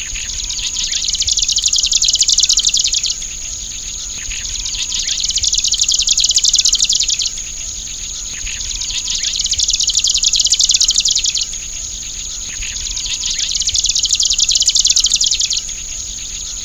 • オオセッカ（センニュウ科）